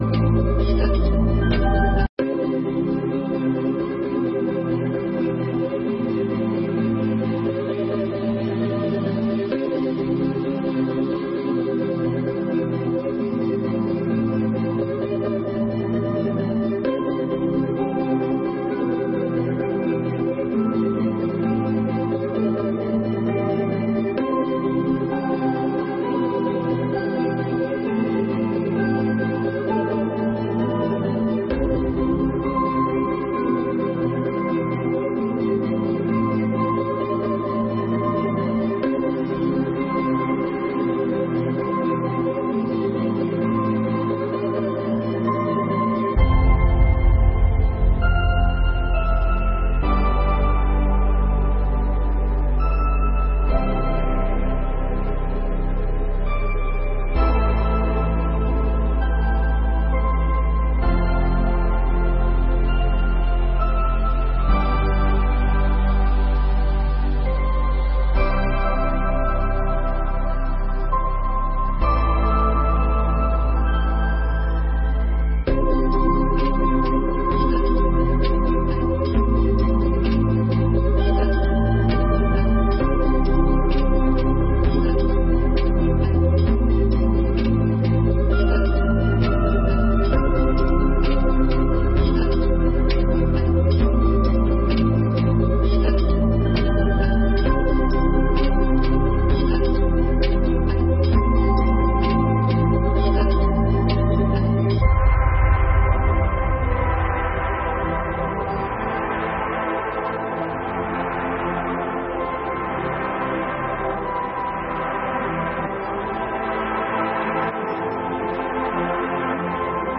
17ª Sessão Ordinária de 2021